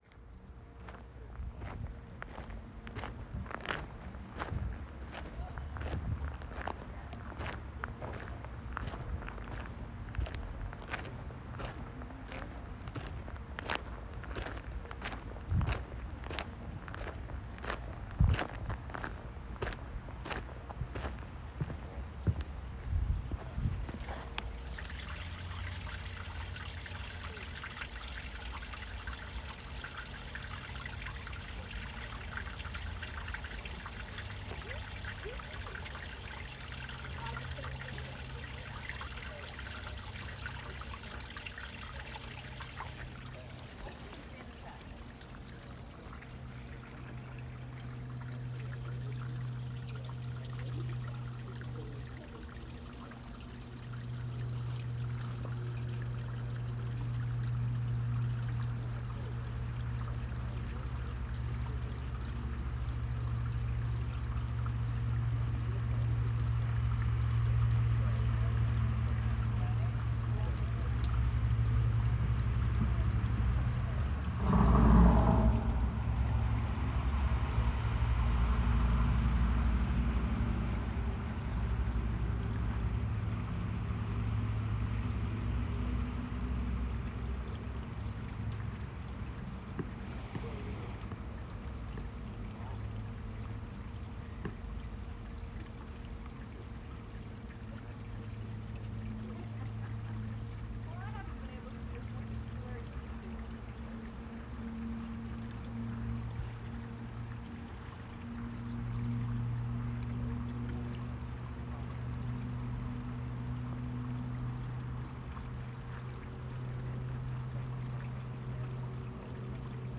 nitobe-small-water.m4a